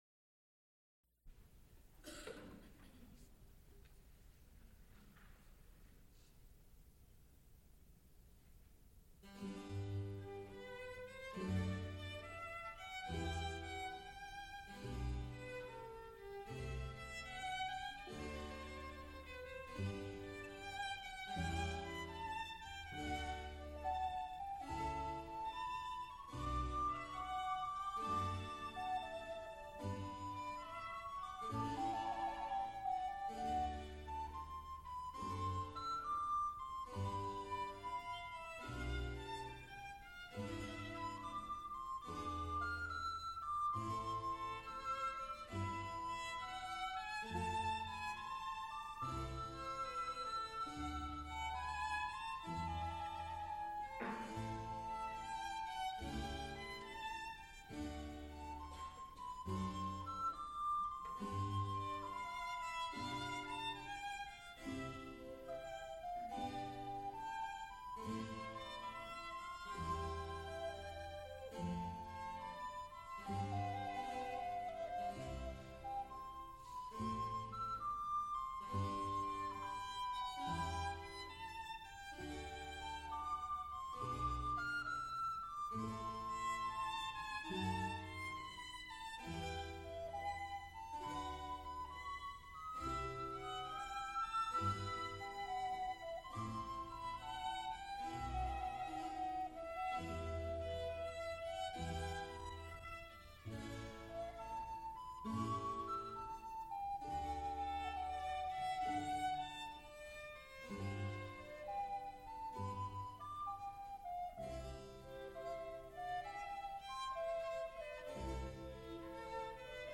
soprano
alto
tenor
unidentified orchestra
harpsichord
Recorded live February 8, 1977, Frick Fine Arts Auditorium, University of Pittsburgh.
Extent 3 audiotape reels : analog, quarter track, 7 1/2 ips ; 7 in.
Harpsichord music Sonatas (Violin and harpsichord) Cantatas, Sacred